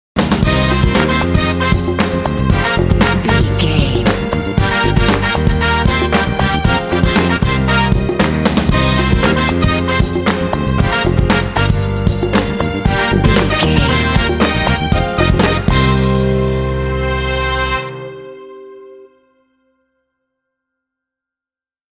Aeolian/Minor
funky
happy
bouncy
groovy
piano
bass guitar
percussion
drums
brass
strings
Funk